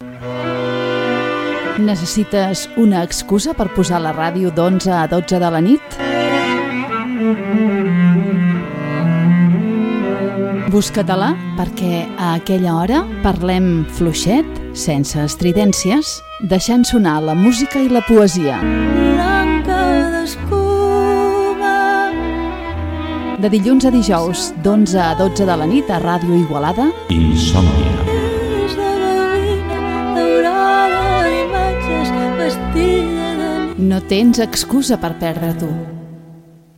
Promoció del programa.
FM